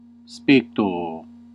Ääntäminen
US Canada: IPA : /ʌʔbˈzɝv/ UK : IPA : /ɒbˈzɜː(ɹ)v/